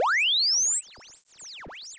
Radio Tuning.wav